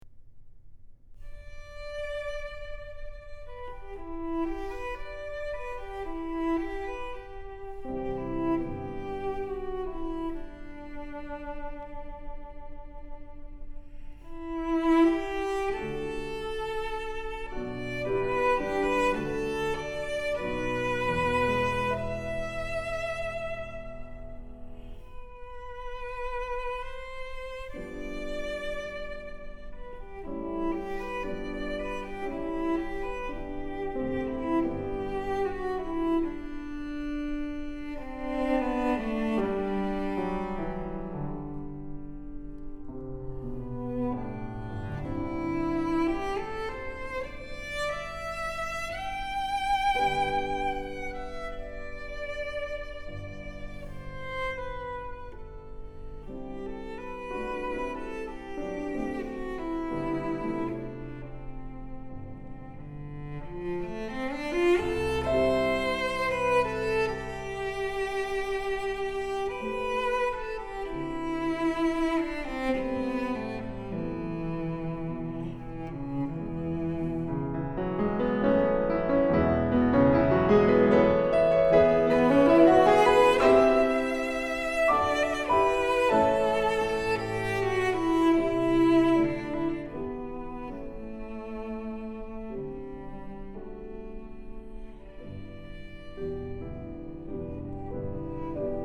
☆才華洋溢、沈穩內斂的大師級演奏風範，不必刻意炫技卻更教人心動！
☆極簡錄音處理，再現最真實質樸且極具典雅氣質的樂器溫潤光澤。